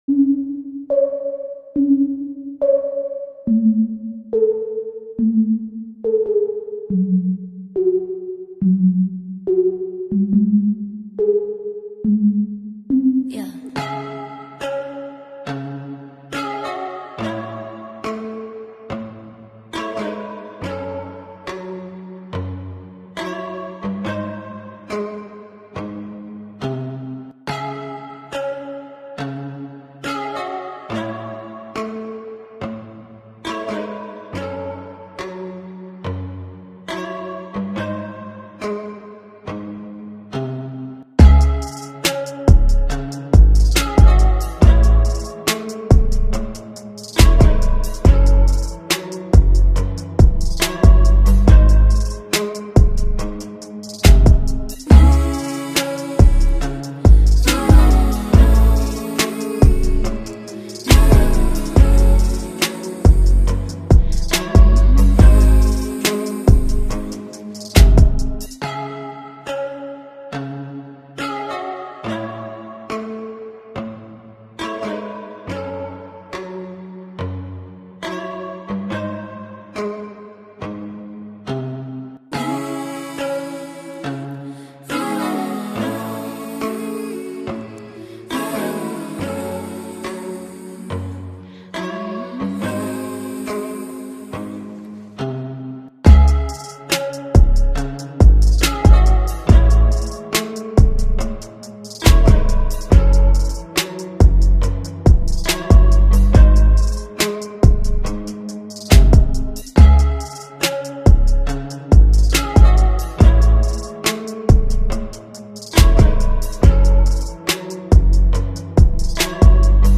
Instrumental beat